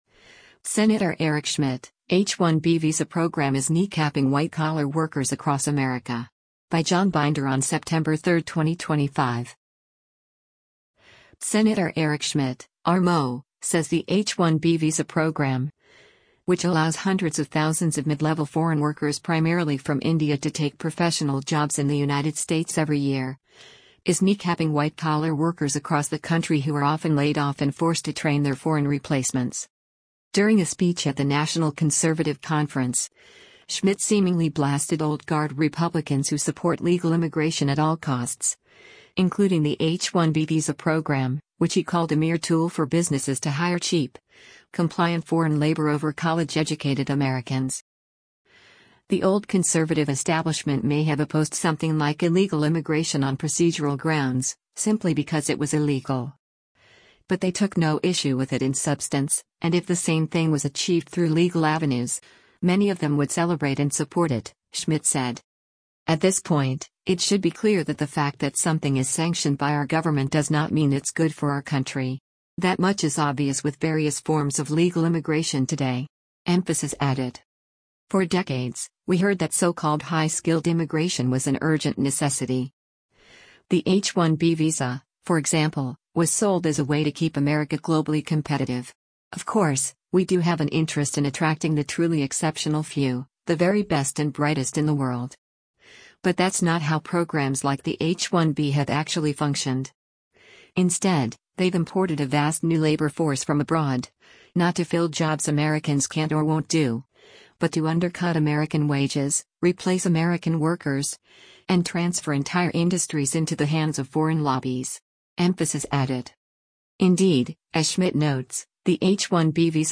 During a speech at the National Conservative Conference, Schmitt seemingly blasted old-guard Republicans who support legal immigration at all costs, including the H-1B visa program, which he called a mere tool for businesses to hire cheap, compliant foreign labor over college-educated Americans.